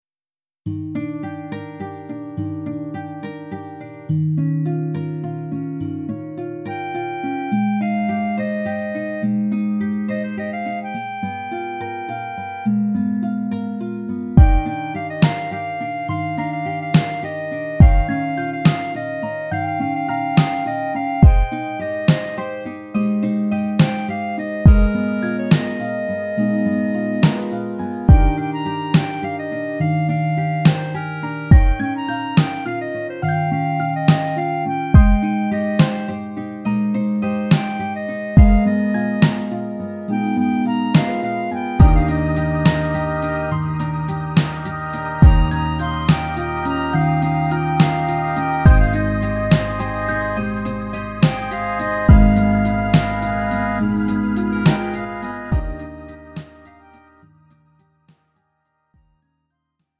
음정 원키 2:33
장르 pop 구분 Lite MR